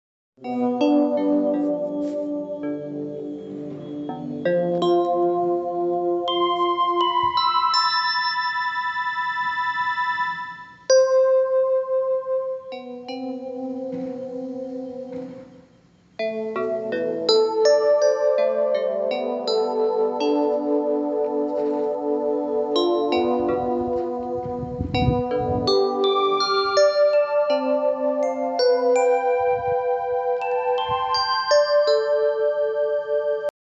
La Música de las Plantas es una experiencia sensorial liderada por Fundación Impulso Verde Kuaspue que permite escuchar las frecuencias eléctricas de las plantas convertidas en música, generando una conexión profunda con la vida vegetal.
Con tecnología especializada, estas señales se transforman en sonido donde cada interpretación es única, influenciada por el ambiente y las personas presentes.